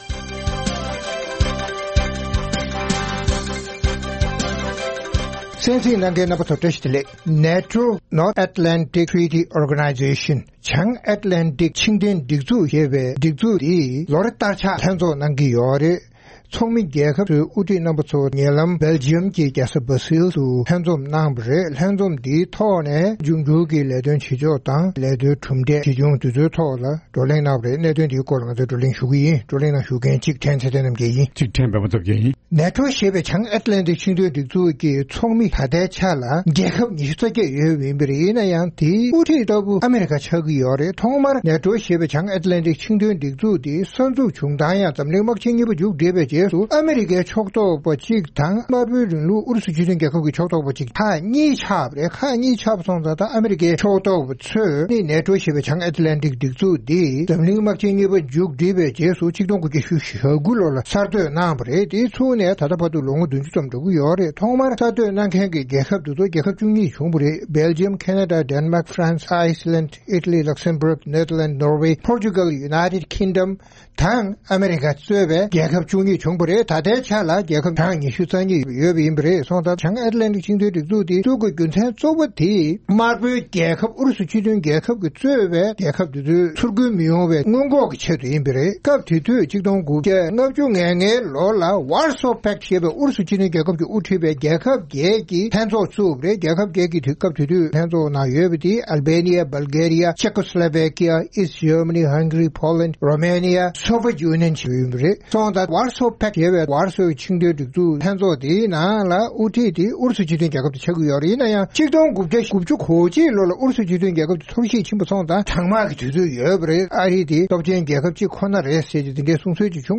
རྩོམ་སྒྲིག་པའི་གླེང་སྟེགས་ཞེས་པའི་ལེ་ཚན་ནང་། NATO ཞེས་བྱང་ཨེཌ་ལན་ཌིག་ཆིངས་དོན་སྒྲིག་འཛུགས་ཀྱི་ལོ་འཁོར་ལྷན་ཚོགས་Belgium རྒྱལ་ས་Brussels རུ་ཚོགས་པའི་ནང་། ཚོགས་མི་རྒྱལ་ཁབ་ཚང་མས་རང་རང་སོ་སོའི་རྒྱལ་ནང་ཐོན་སྐྱེད་རིན་ཐང་བསྡོམས་འབོར་ནས་བརྒྱ་ཆ་གཉིས་དྲག་པོའི་ཐོག་ལོ་འཁོར་སྔོན་རྩིས་གཏན་འབེབས་གནང་རྒྱུར་གླེང་སློང་ཤུགས་ཆེ་བྱུང་བ་སོགས་ཀྱི་སྐོར་རྩོམ་སྒྲིག་འགན་འཛིན་རྣམ་པས་བགྲོ་གླེང་གནང་བ་ཞིག་གསན་རོགས་གནང་།